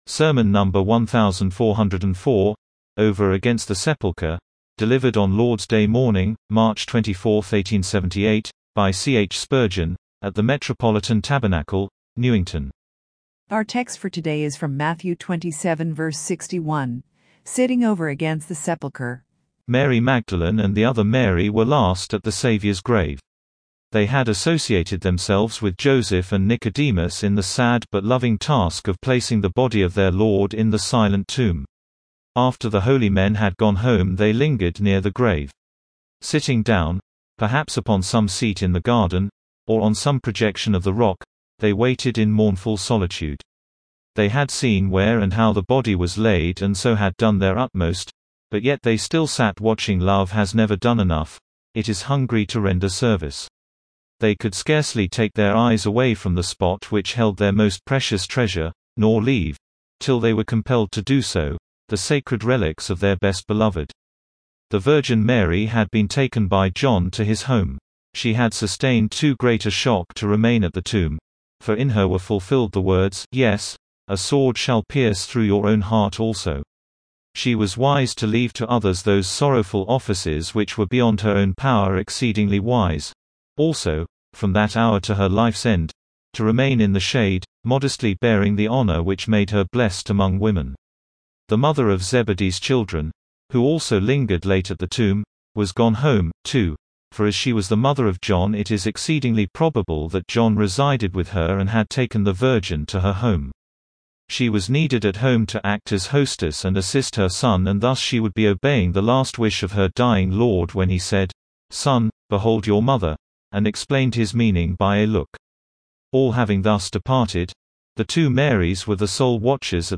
Sermon number 1,404, OVER AGAINST THE SEPULCHER